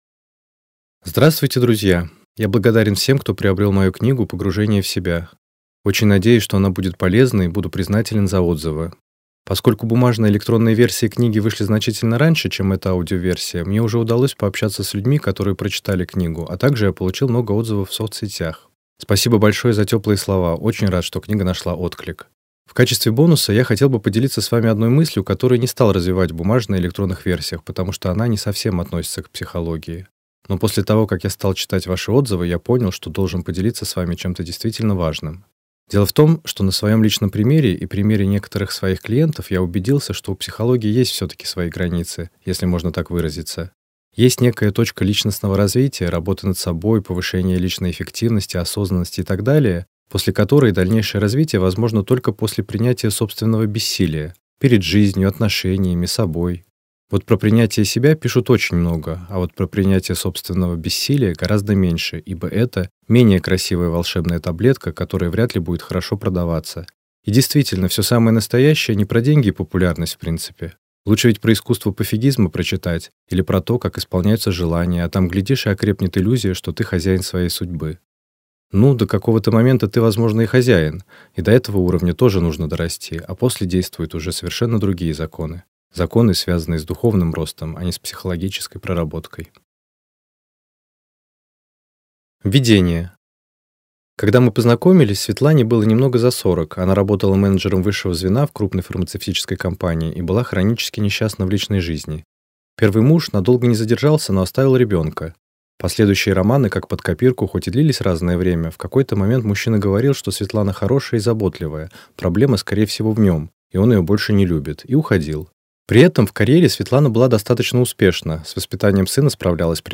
Аудиокнига Погружение в себя | Библиотека аудиокниг